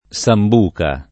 Samb2ka] s. f. («strumento musicale»; «liquore») — sim. il top. S. (di rado ormai con l’art., la S.) — due comuni: S. Pistoiese (Tosc.) e S. di Sicilia, fino al 1923 S. Zabut (Sic.)